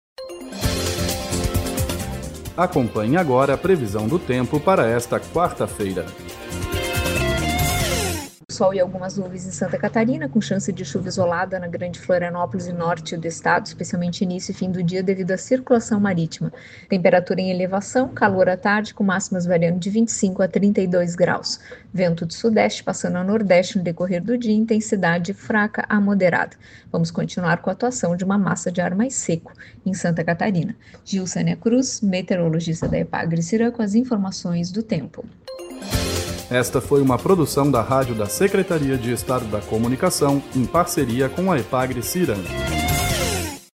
Previsão do tempo para quarta-feira, 18/12/2024